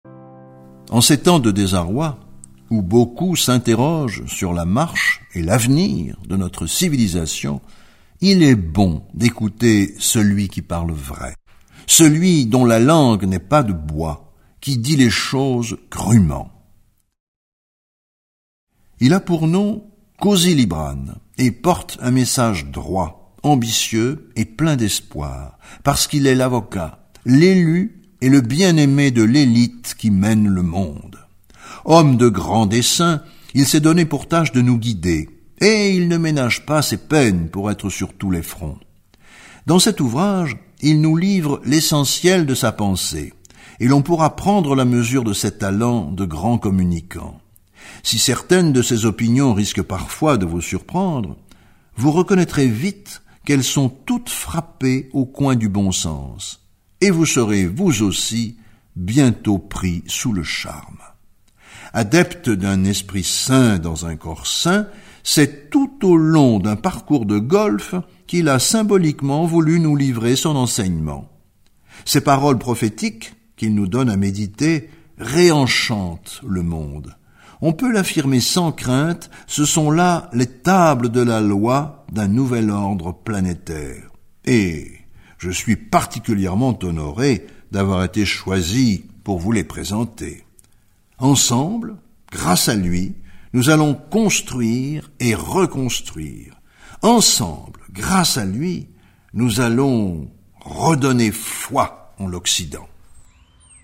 Click for an excerpt - Le prophète du libéralisme de Michel Piquemal